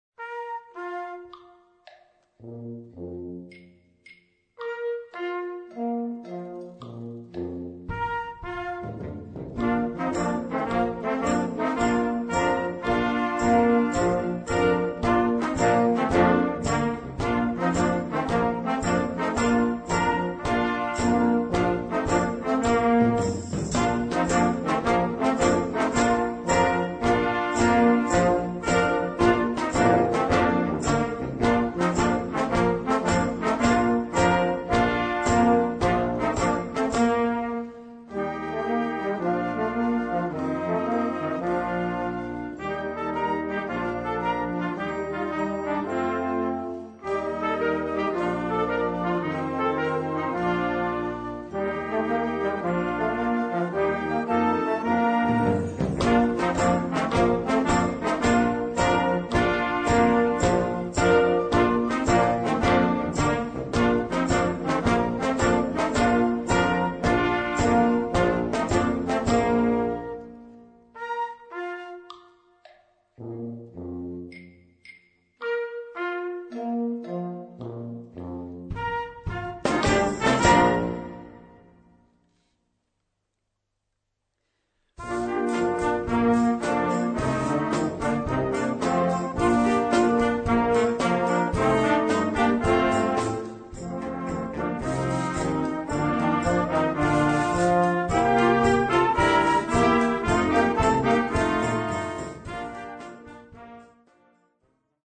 Des gouttes de pluie en automne.
Partitions pour ensemble flexible, 4-voix + percussion.